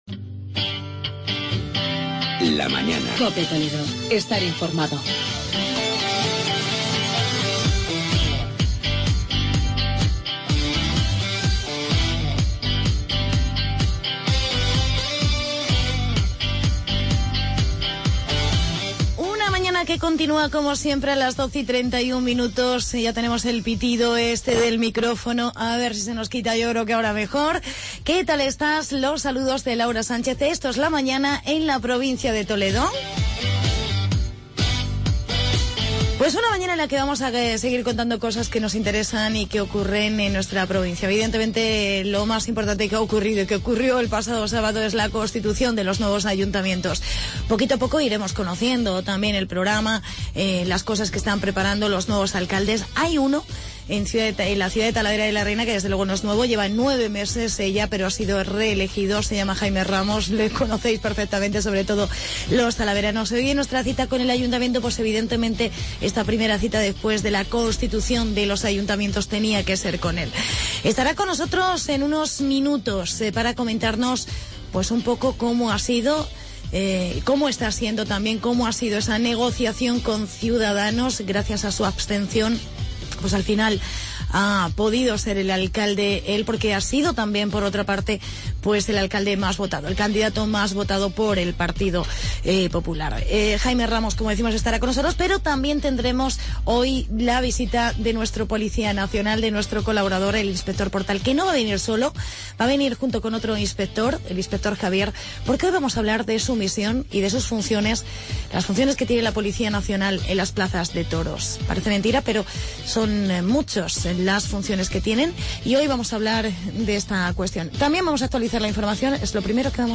Entrevistamos al alcalde de Talavera, Jaime Ramos